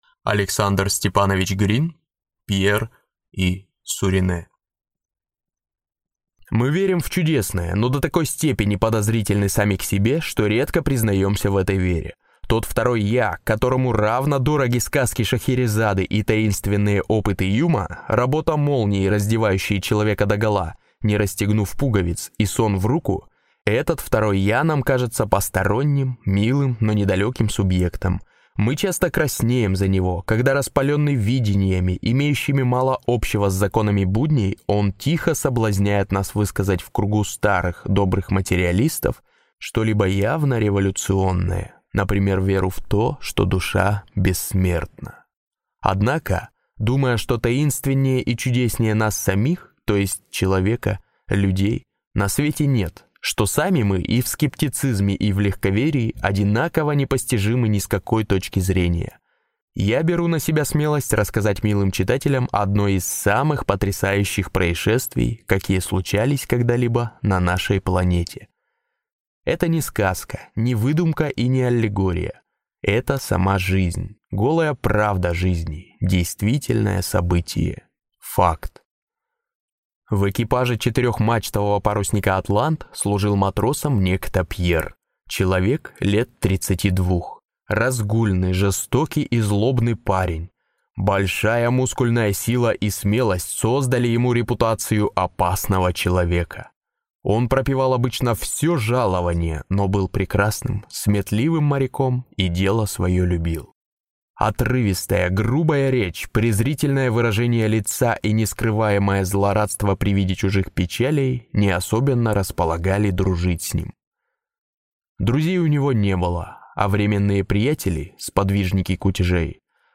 Аудиокнига Пьер и Суринэ | Библиотека аудиокниг